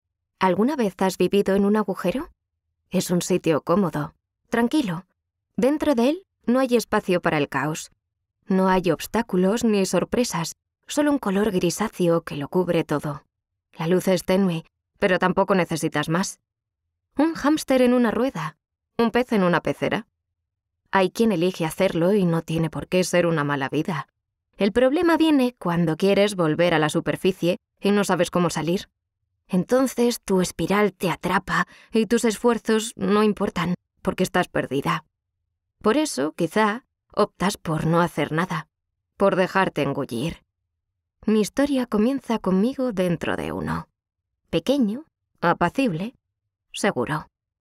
Young, Urban, Cool, Reliable, Natural
Explainer